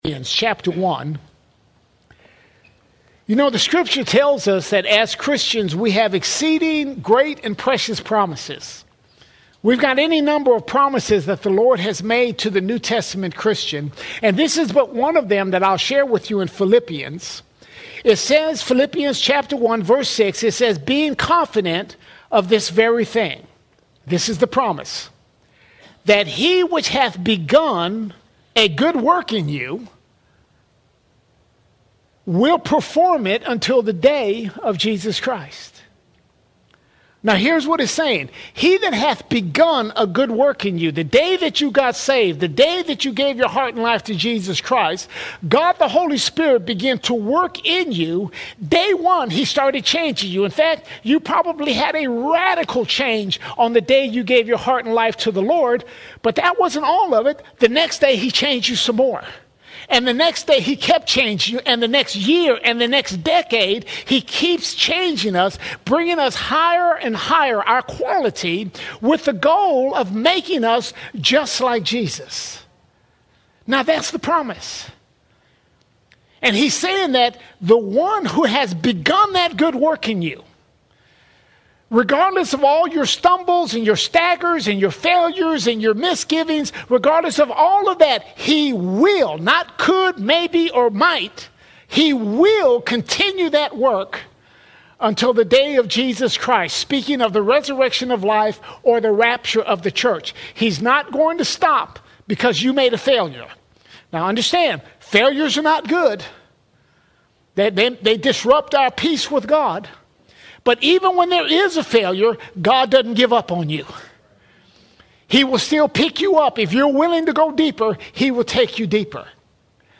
20 October 2025 Series: Sunday Sermons All Sermons Victory in Failure Victory in Failure Even in failure, God’s promise stands.